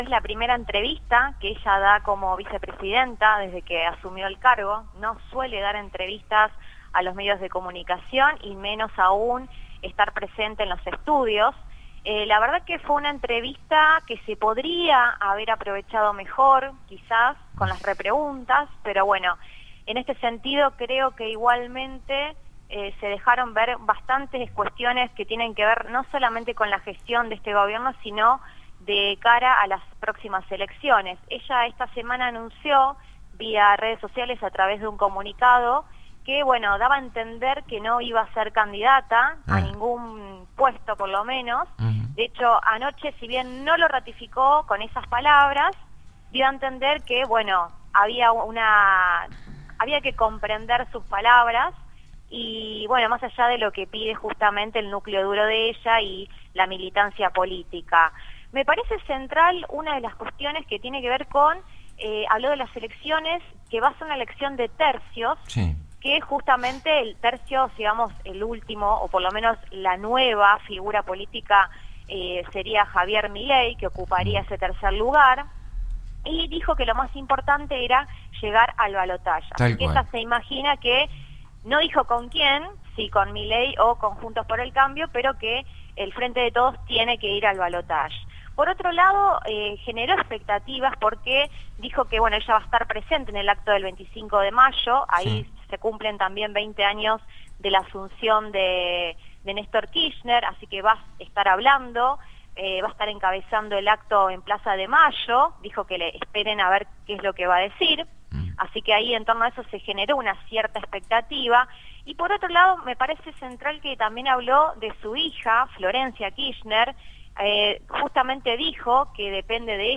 pasó por los micrófonos de Radio Del Sur FM 90.5 para realizar su tradicional columna política y económica. Este viernes por la mañana se refirió a la entrevista que la actual vicepresidenta de la Nación brindó al medio C5N y habló sobre las posibilidades de que Cristina revierta su decisión y sea finalmente candidata a presidenta.